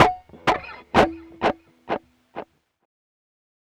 Track 10 - Guitar 01.wav